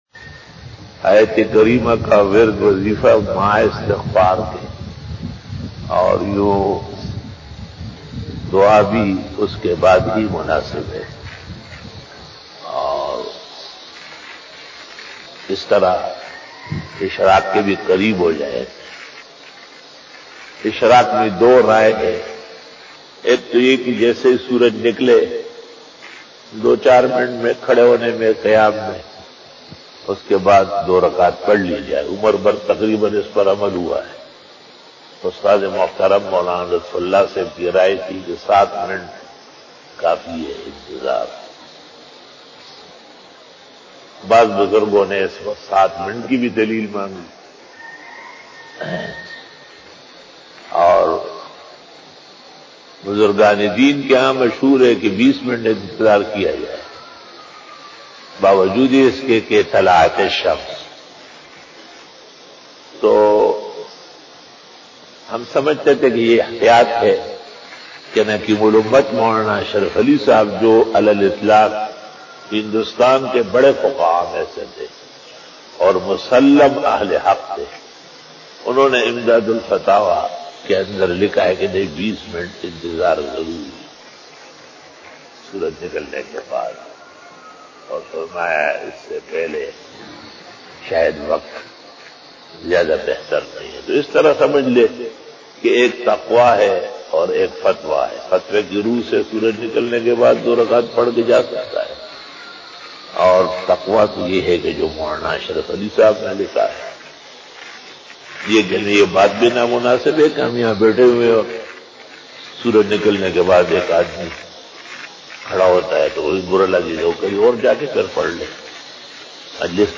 Fajar bayan
بیان بعد نماز فجر بروز جمعہ